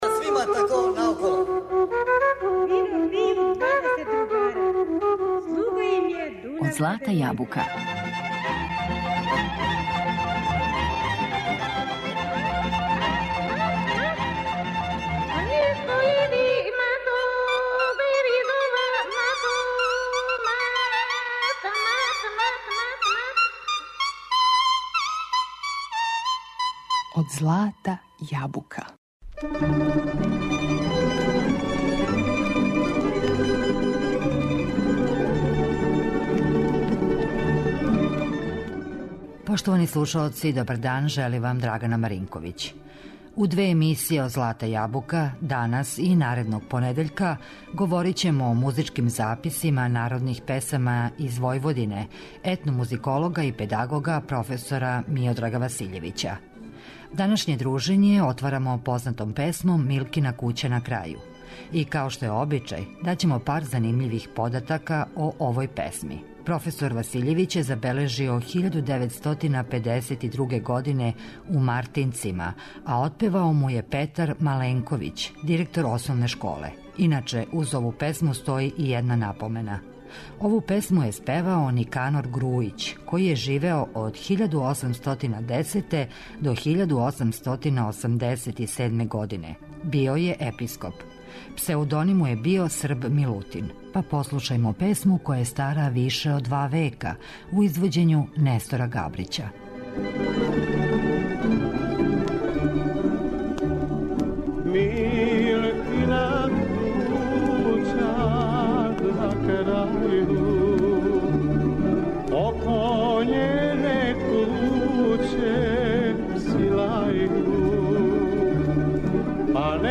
Емисија резервисана за изворну народну музику